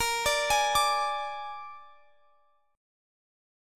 Listen to A#7 strummed